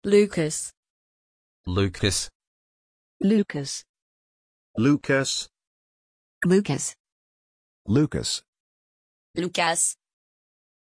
Aussprache von Loukas
pronunciation-loukas-en.mp3